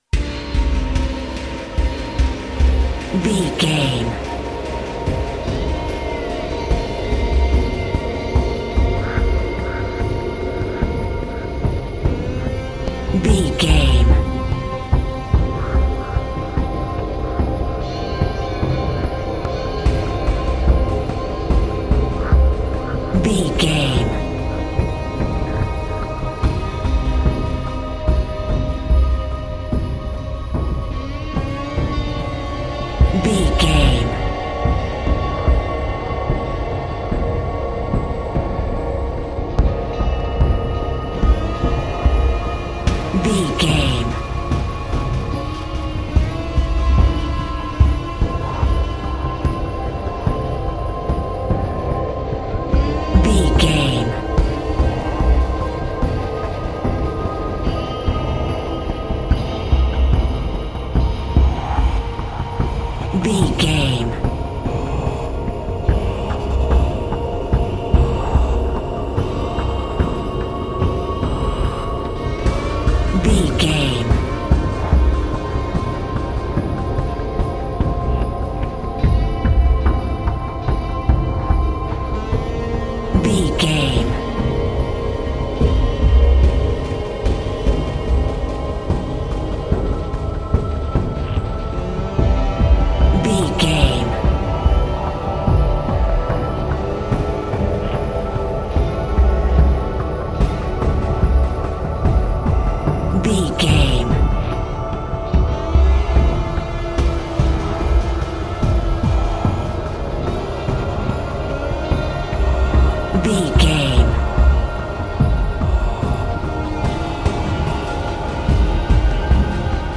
Thriller
Aeolian/Minor
synthesiser
drum machine
tension
ominous
dark
suspense
haunting
creepy